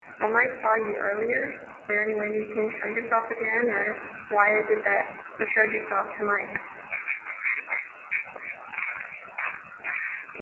It only showed up on their recorder.
There's has more white noise in it, which gives the ghosts more to work with in theory.